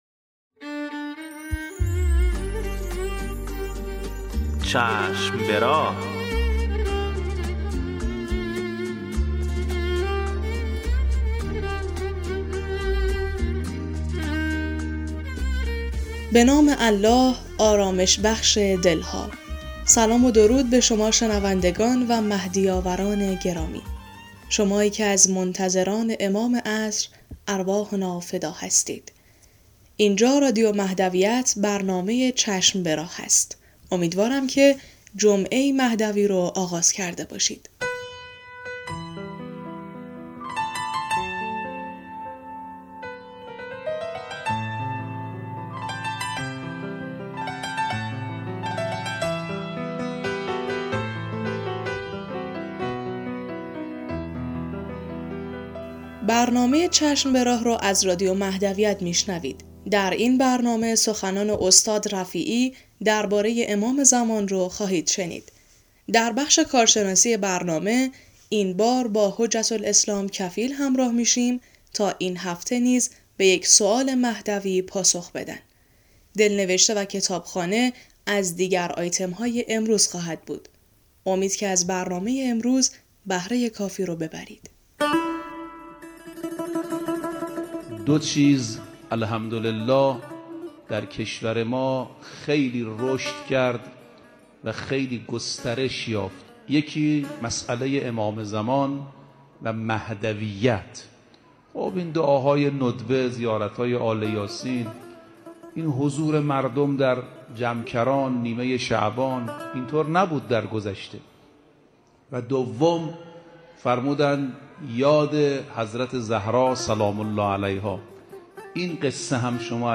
قسمت صد و سی و نهم مجله رادیویی چشم به راه که با همت روابط عمومی بنیاد فرهنگی حضرت مهدی موعود(عج) تهیه و تولید شده است، منتشر شد.